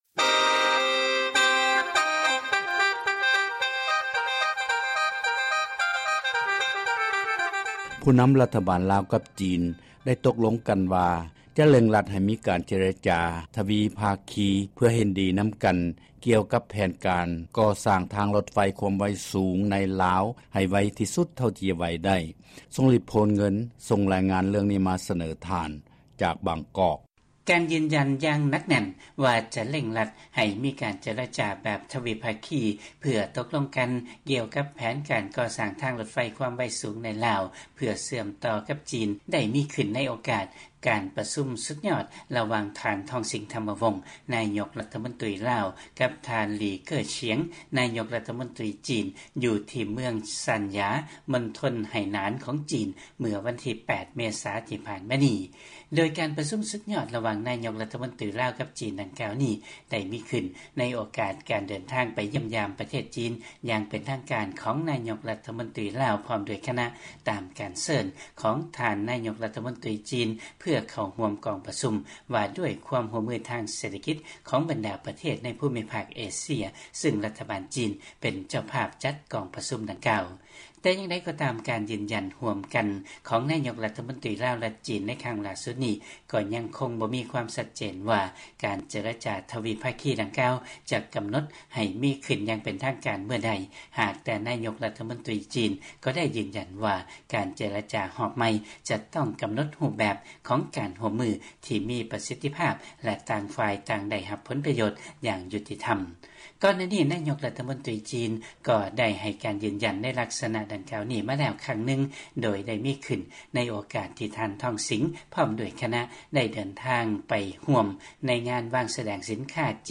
ຟັງລາຍງານເລື້ອງ ແຜນການ ກໍ່ສ້າງທາງລົດໄຟຄວາມໄວສູງ ໃນລາວ.